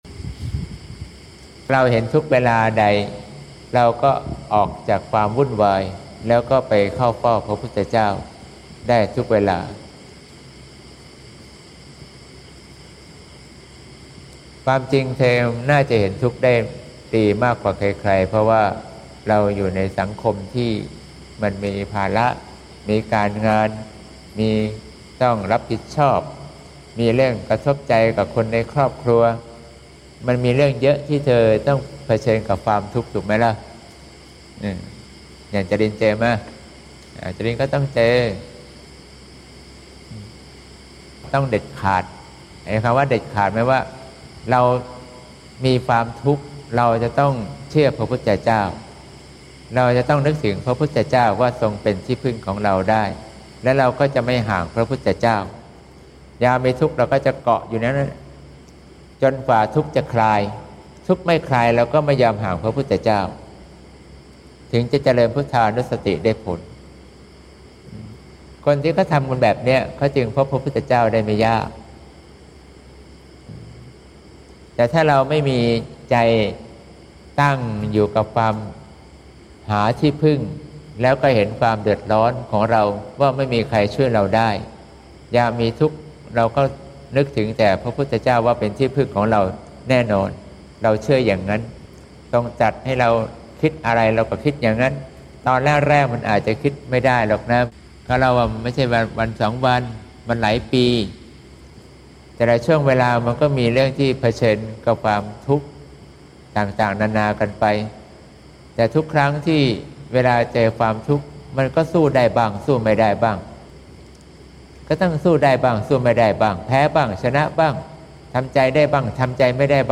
เสียงธรรม